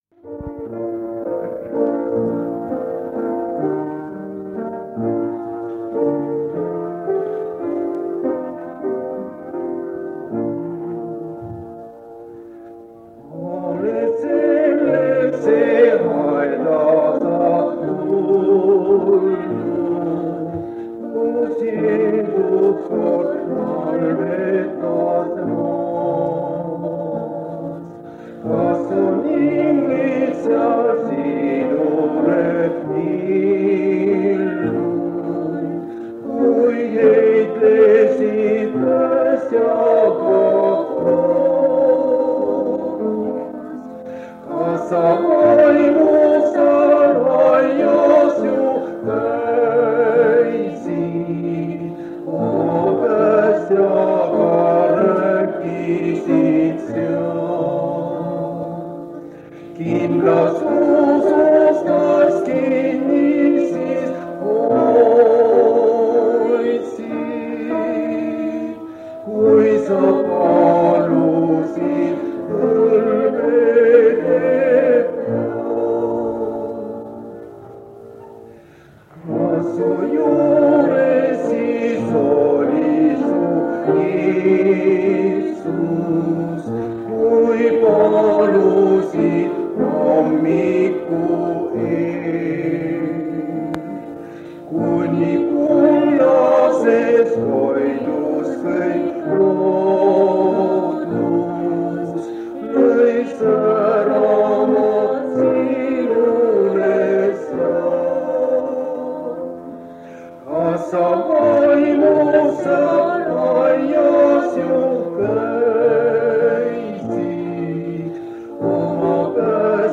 Jutlused
Kingissepa linnas on evangeeliuminädal.
On ka muusikat. Lindistus pärit vanalt lintmaki lindilt.